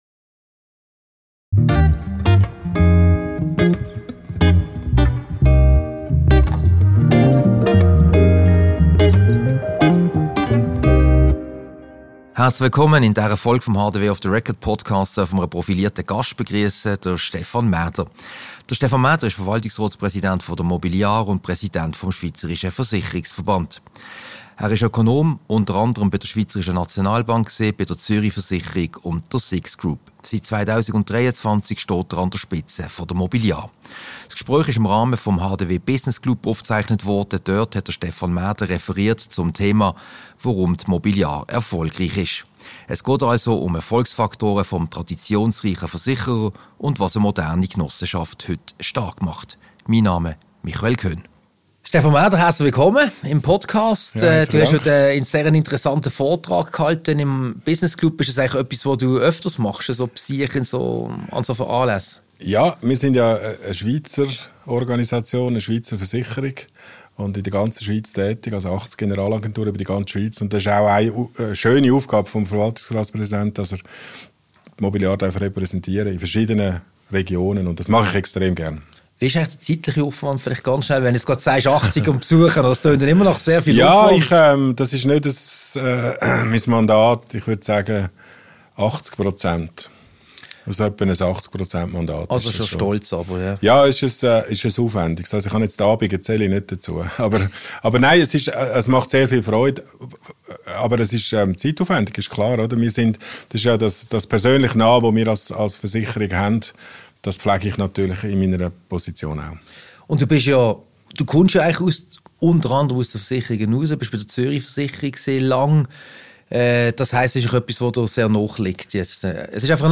Diese Podcast-Ausgabe wurde anlässlich des HDW Business Club Lunchs vom 10. April 2025 im Haus der Wirtschaft HDW aufgezeichnet.